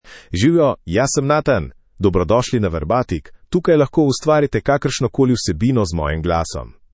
Nathan — Male Slovenian AI voice
Nathan is a male AI voice for Slovenian (Slovenia).
Voice sample
Male
Nathan delivers clear pronunciation with authentic Slovenia Slovenian intonation, making your content sound professionally produced.